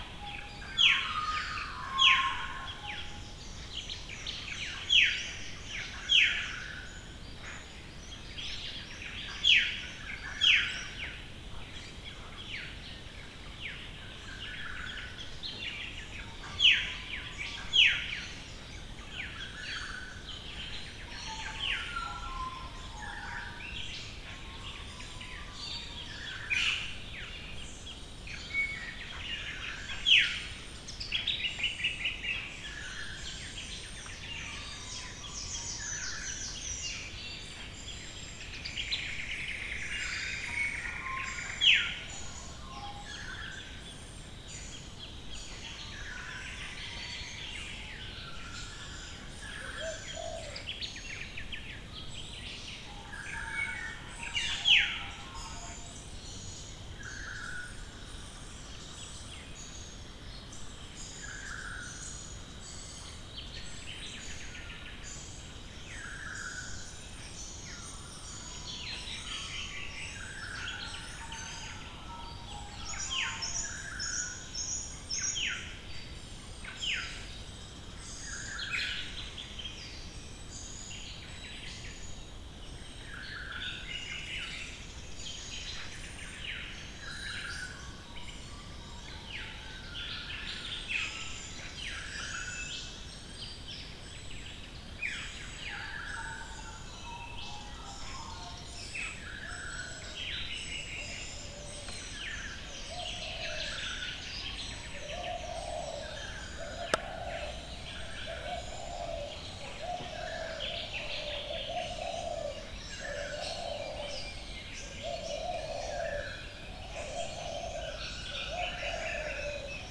Australian Birdcalls in the early morning Sound Effect — Free Download | Funny Sound Effects
The sounds of birdcalls in Port Stephens, NSW, at around 6am in Spring time.